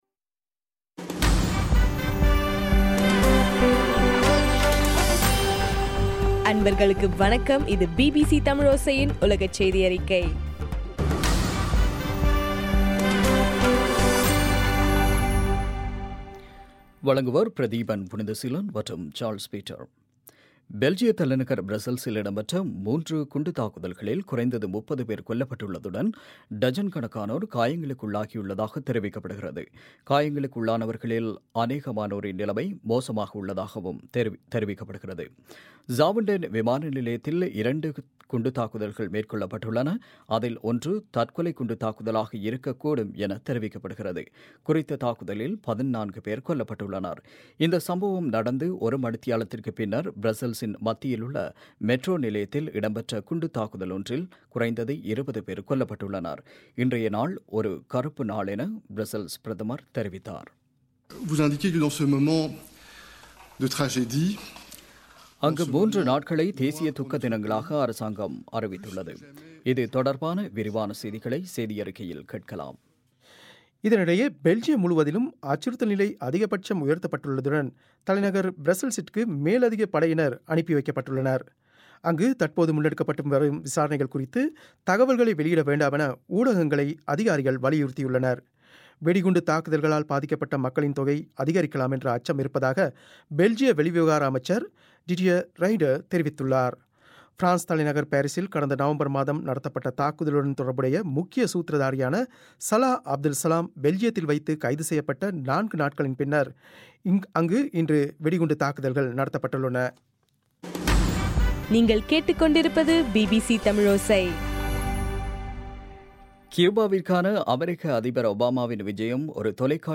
மார்ச் 22, 2016 பிபிசி செய்தியறிக்கை